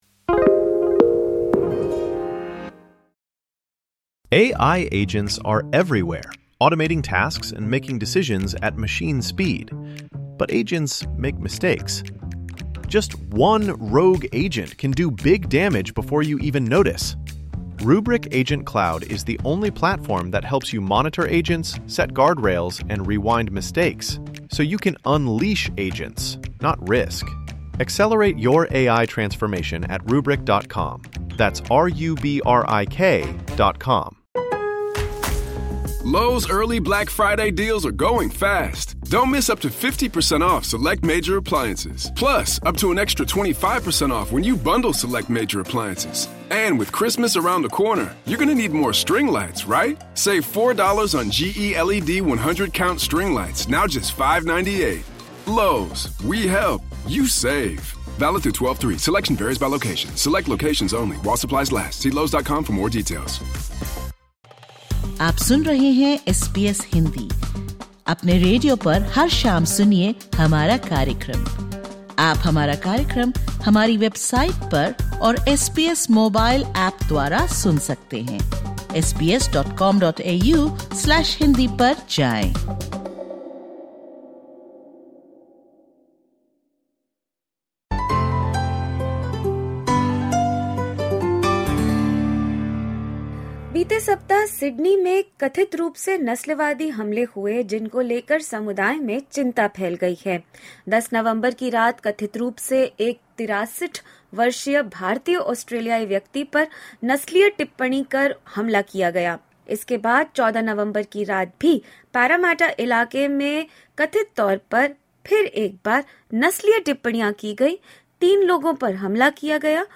This episode looks at the recent alleged racist attacks in Sydney and their impact on the Indian-Australian community. Featuring voices of victims, experts and community leaders, it discusses rising concerns, underreporting and the spread of online and offline racism.
Disclaimer: Views expressed in the podcast are that of the interviewee.